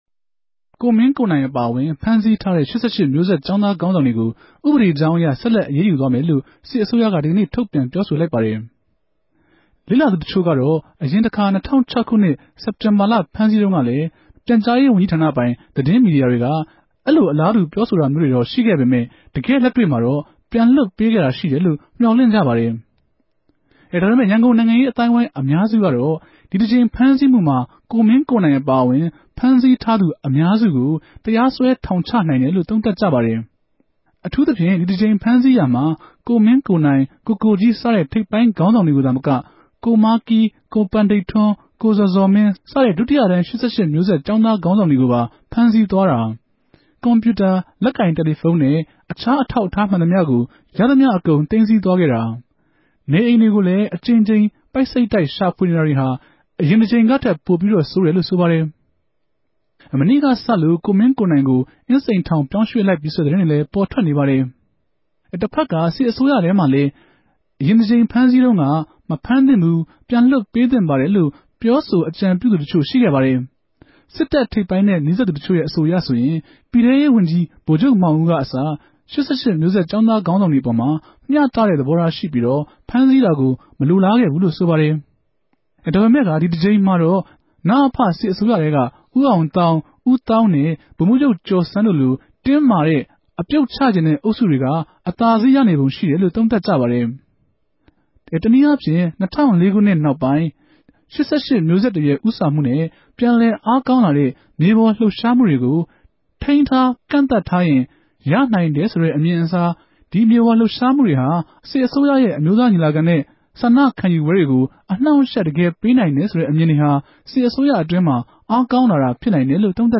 ူမန်မာိံိုင်ငံမြာ လက်ရြိ ူဖစ်ပေၞနေတဲ့ လောင်စာဆီဈေး ကဵဆင်းရေး လြပ်ရြားမနြဲႛ ပတ်သက်္ဘပီး လတ်တလော အေူခအနေတေကြို RFAမြ ဆက်သြယ်မေးူမန်း္ဘပီး သုံးသပ် တင်ူပထားပၝတယ်။